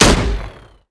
AutoGun_1p_01.wav